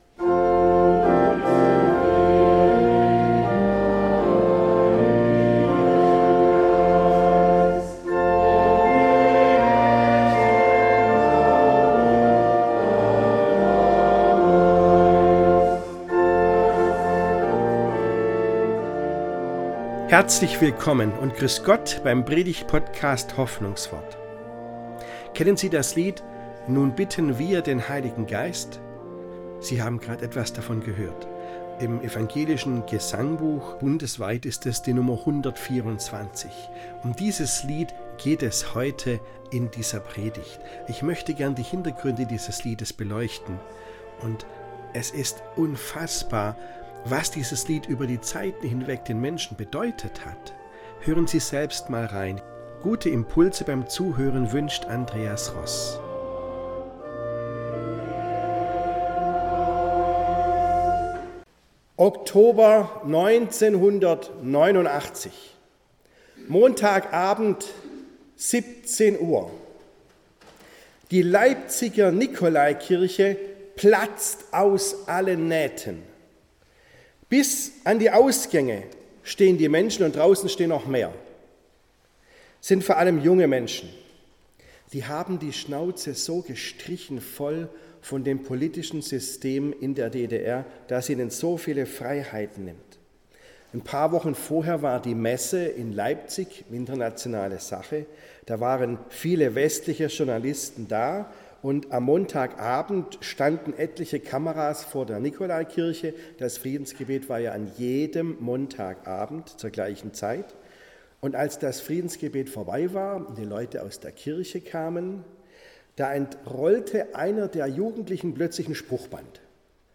Lieblingslieder: Nun bitten wir den Heiligen Geist ~ Hoffnungswort - Predigten